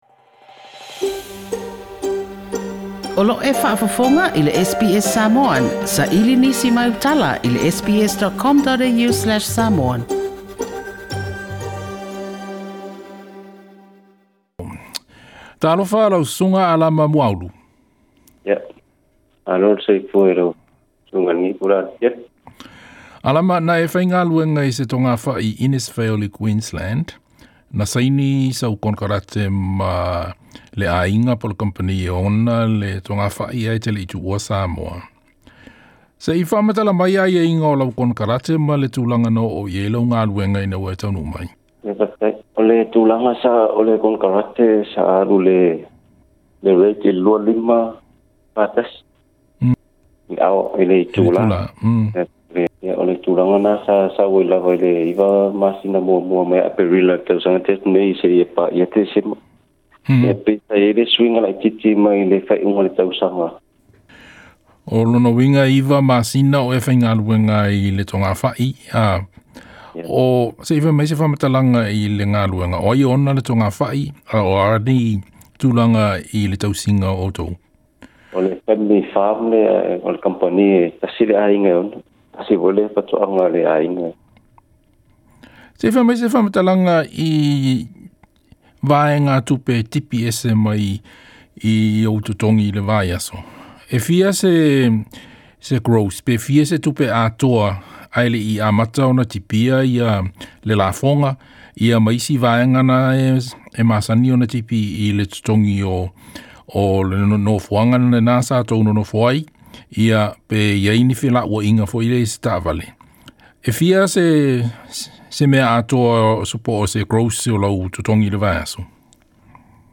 SBS Samoan